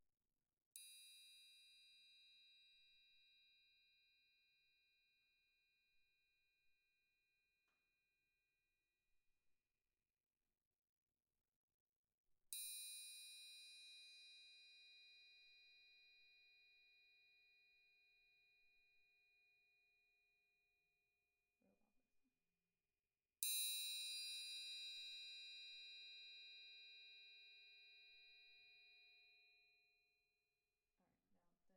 Single_Triangle_Hits_soft_medium_loud
ding loud music ping ring ringing soft ting sound effect free sound royalty free Music